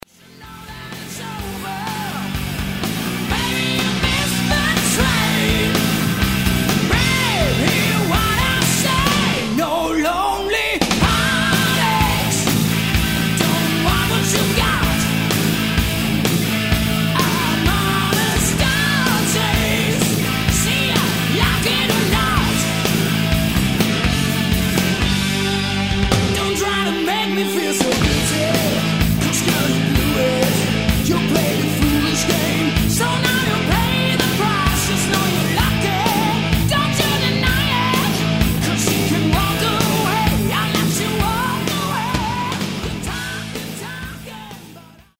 Swiss Hard Rock band
For pure hard driven rock and roll